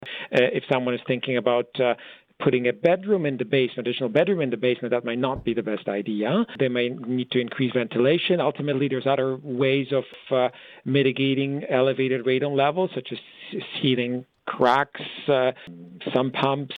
Medical Officer of Health Doctor Piotr Oglaza tells Quinte News 650 homeowners have already signed up and there are 250 spaces remaining.